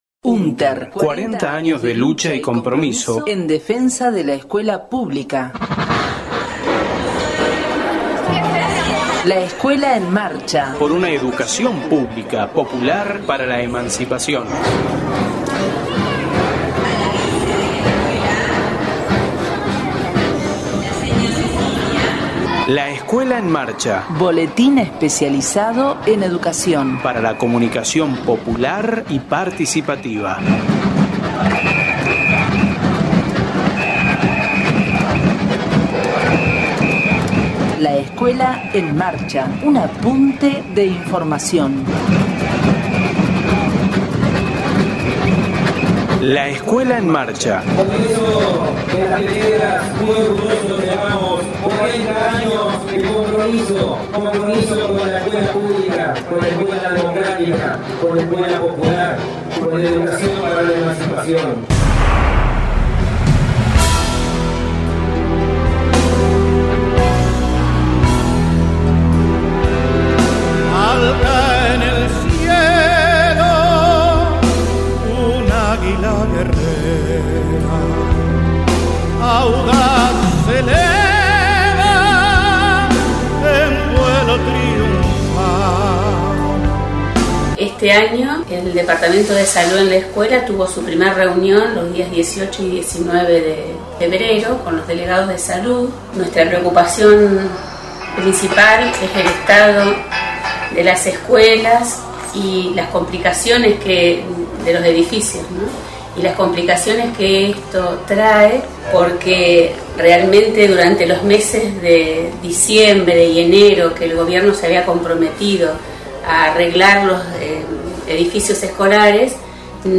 LEEM, radio 2/03/15.